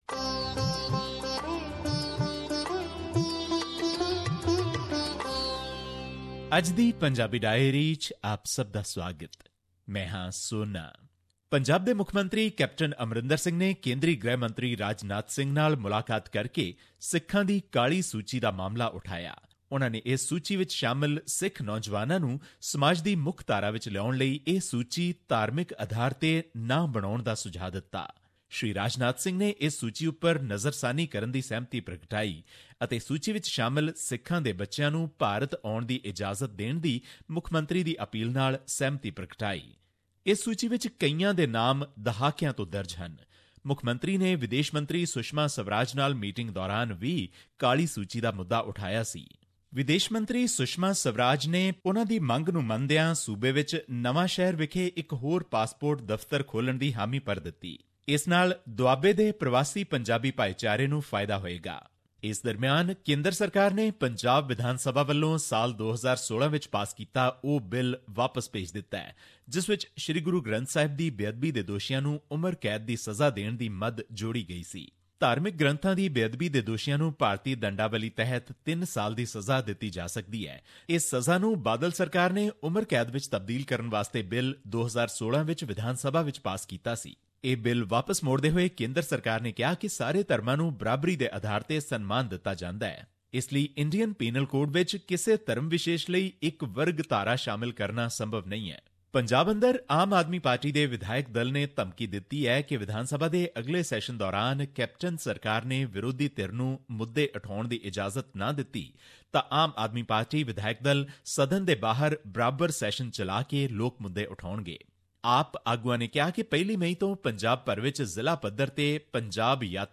His report was presented on SBS Punjabi program on Monday, April 24, 2017, which touched upon issues of Punjabi and national significance in India. Here's the podcast in case you missed hearing it on the radio.